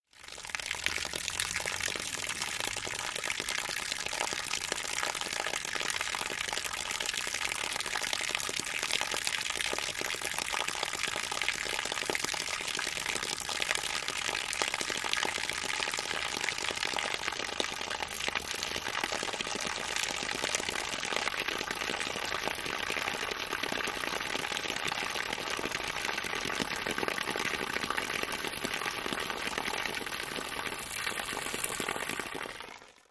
RainFallOnMudStream TE37492
Rain Fall On Mud Stream